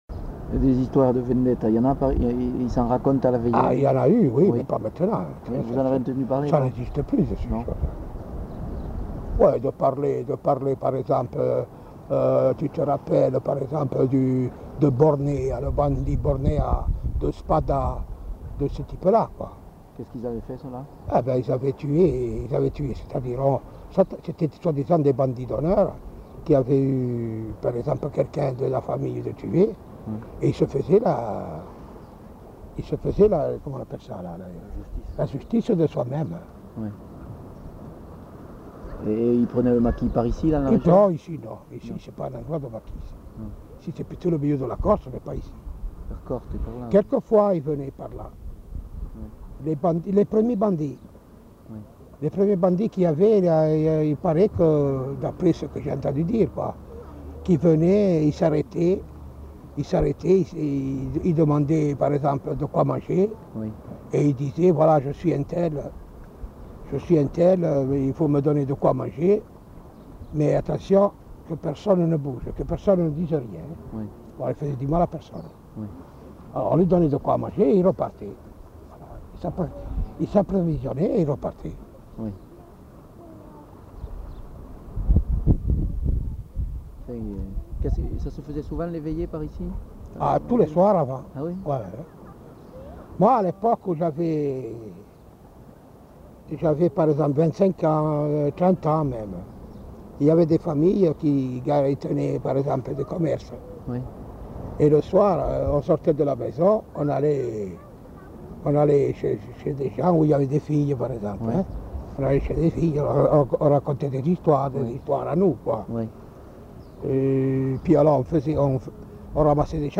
Lieu : Bastia (région)
Genre : témoignage thématique